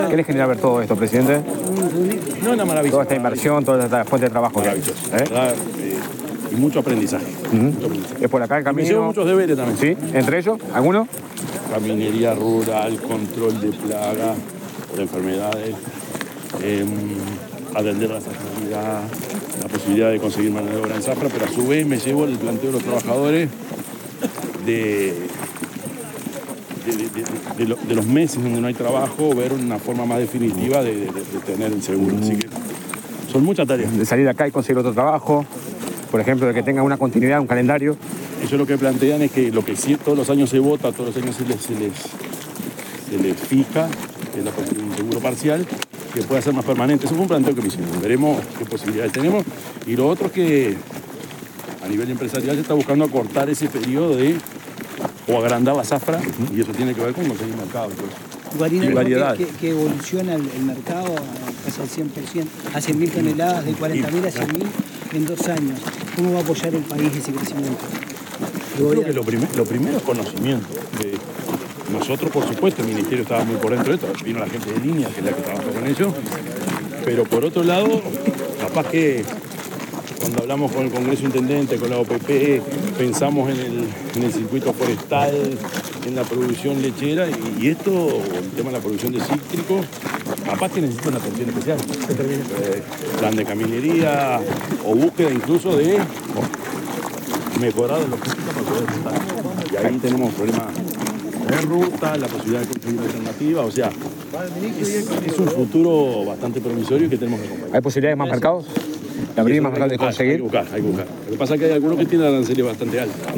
Declaraciones del presidente Yamandú Orsi en Salto
El presidente de la República, profesor Yamandú Orsi, brindó declaraciones a la prensa en el departamento de Salto, durante la inauguración de la